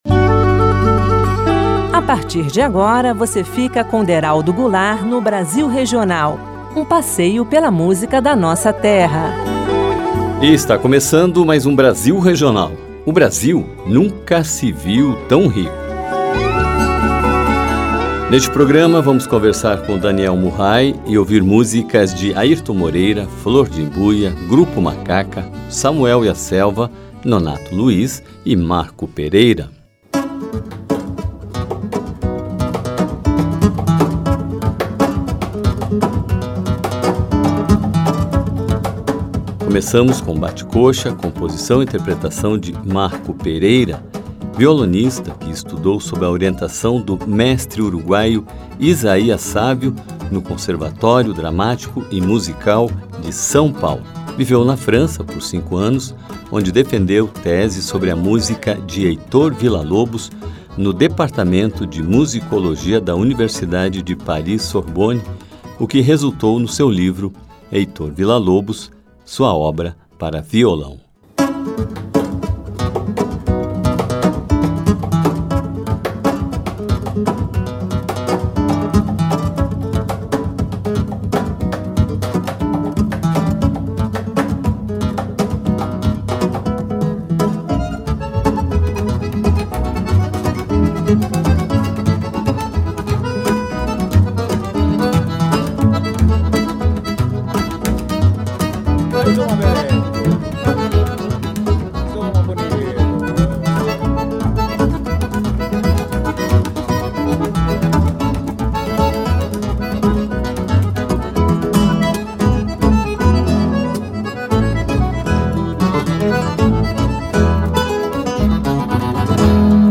harmonias e polirritmias em violão-solo.